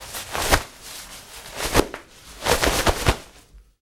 Cloth Rain Coat Shaking Sound
household